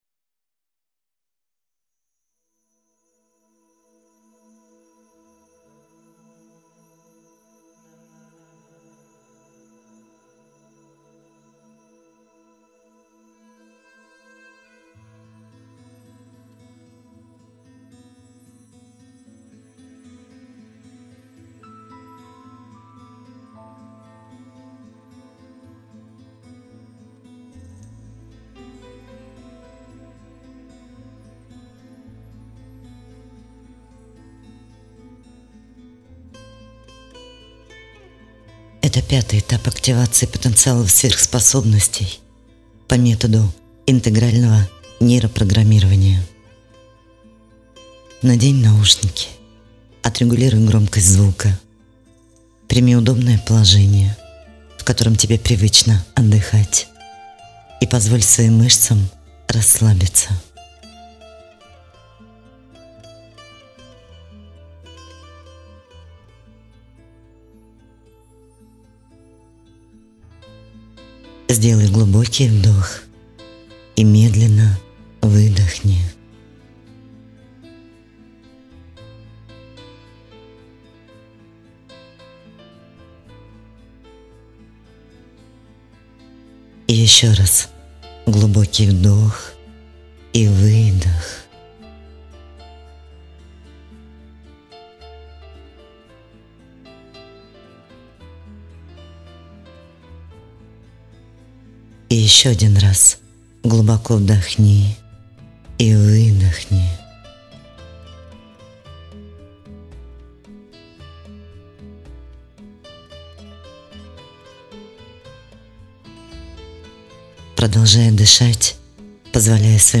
Формат 5-го этапа активации – аудиосеанс. Продолжительность сеанса: 20 минут Обязательное условие: сеанс принимать в наушниках 00 : 00 00 : 00 00 : 00 СКАЧАТЬ ВЕРНУТЬСЯ В МЕНЮ